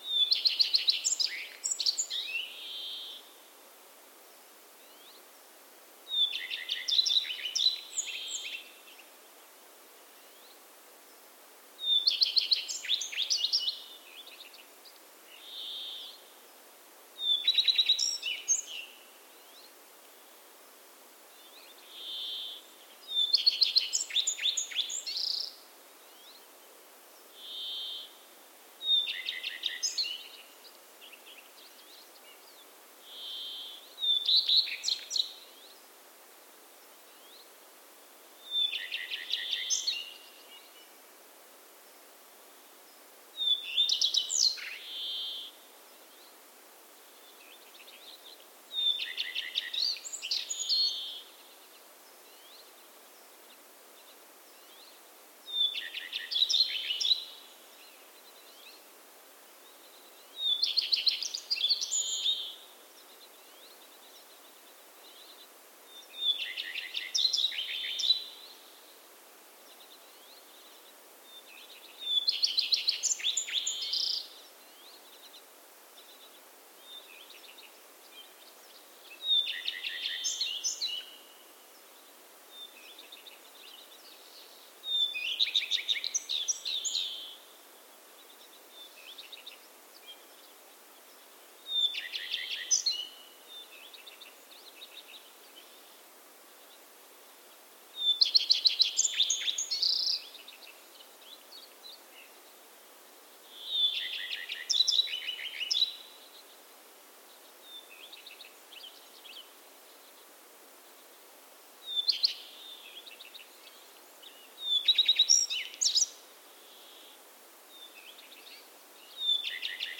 Kuuntele: Leppälintu, asutusalueen kaunis tuntematon
Leppälintu on suurelle yleisölle tuntemattomampi kuin esimerkiksi kirjosieppo, vaikka molempien äänen voi kuulla monissa puistoissa ja puutarhoissa.